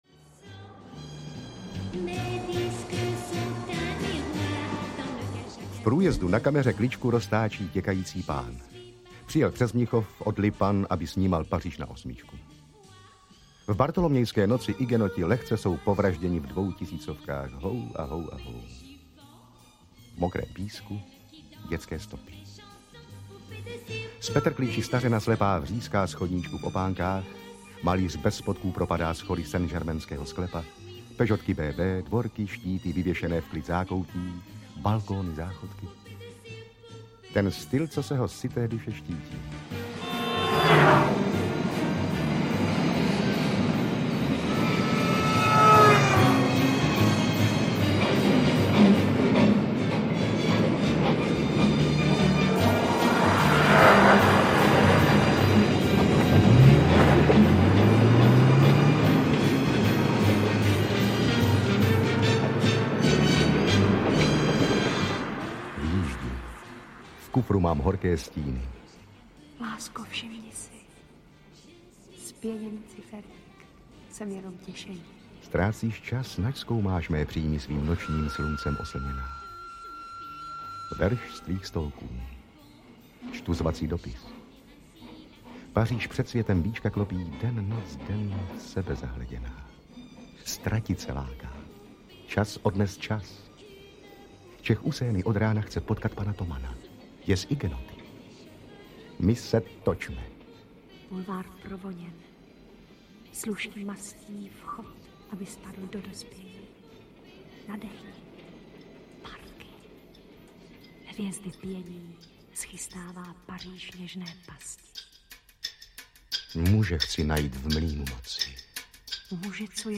Paříž, Paříž! audiokniha
Ukázka z knihy